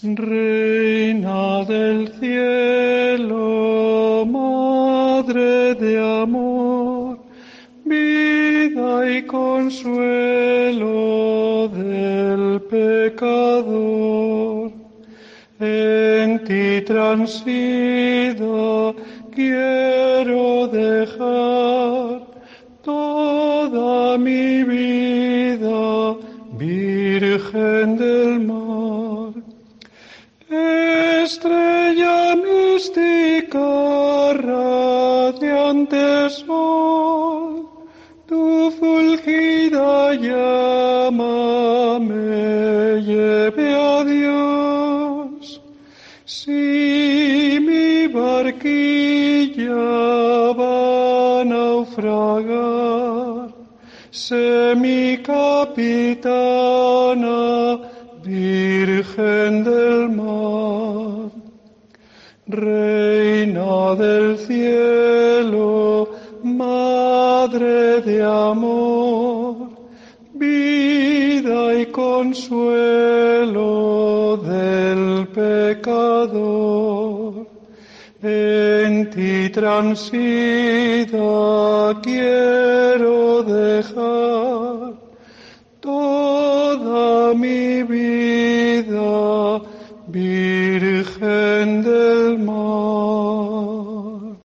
Himno antiguo a la Virgen del Mar